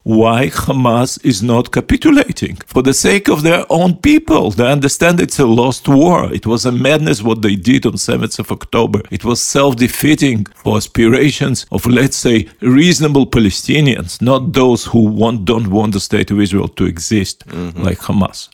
O svemu smo u Intervjuu tjedna Media servisa razgovarali s izraelskim veleposlanikom u Hrvatskoj Garyjem Korenom koji je poručio: "Mi nismo ludi ljudi, ne želimo još desetljeća nasilja; lideri trebaju glasno reći Hamasu da je dosta!"